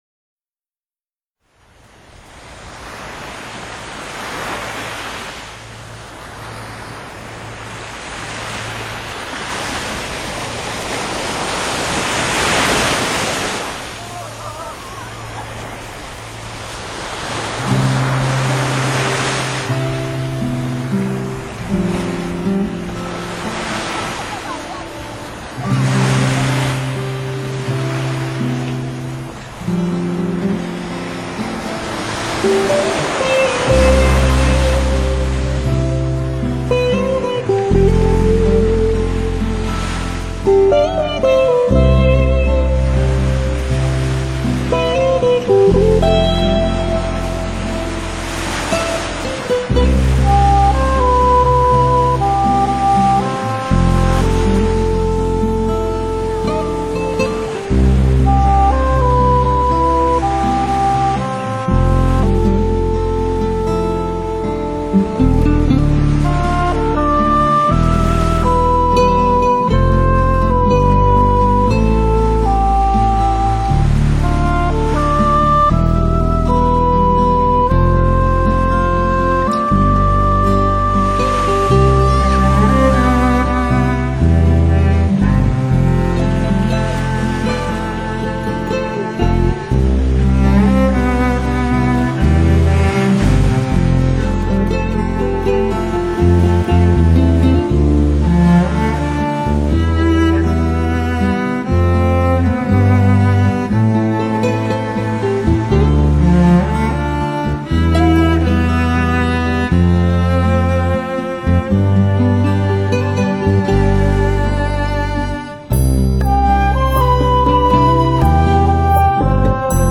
专辑语言：纯音乐
台湾海洋声音与轻音乐精彩结合，再度引爆自然音乐话题。
New Age、轻爵士、佛朗明哥吉他、古典钢琴小品、民谣风…10首音乐10种风格，实在好听的创作曲献给天下知音人。
台湾沿海实地录音，临场海浪声绝无仅有。
英国管
曲笛
吉他
打击乐
海声录音
那天，在恒春的白砂村录海的声音，看到了这样的画面。
阳光很阳光，蓝天很蓝天，小朋友的哭叫和潮浪声混在一起。